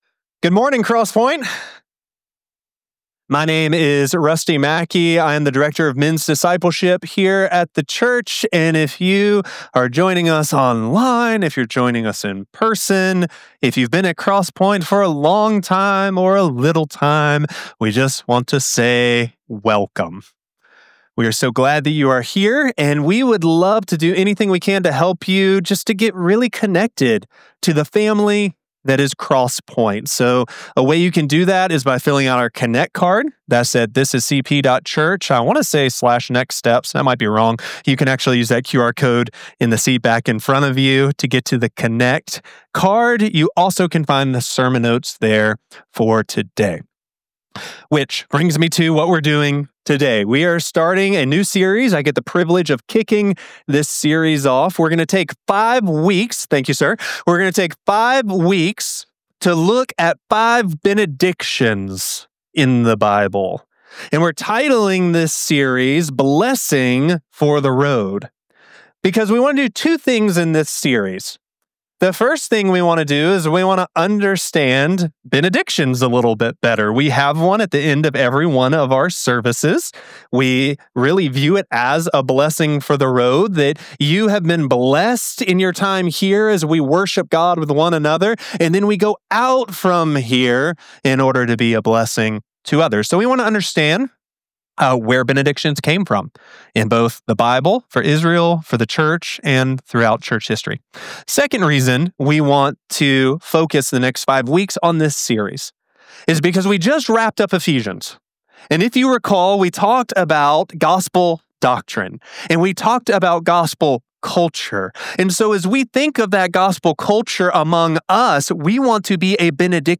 Sermon Only- Mastered 7.13.25.mp3